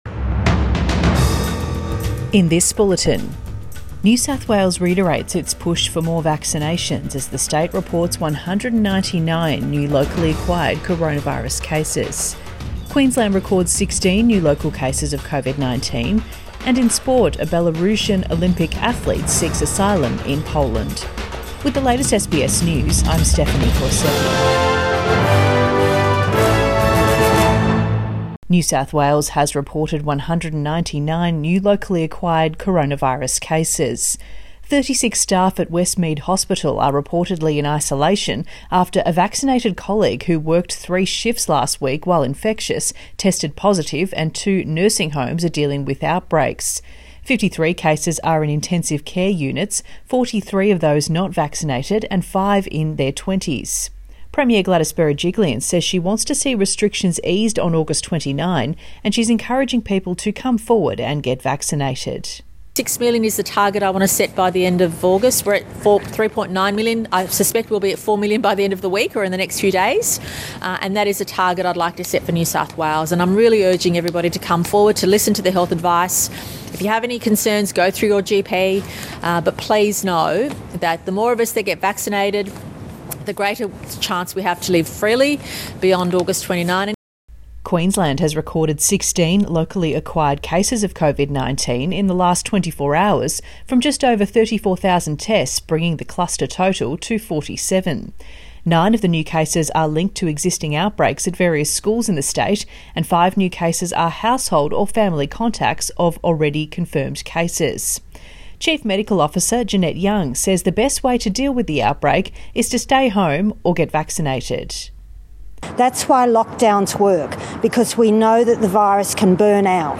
Midday bulletin 3 August 2021